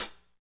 HH HH119.wav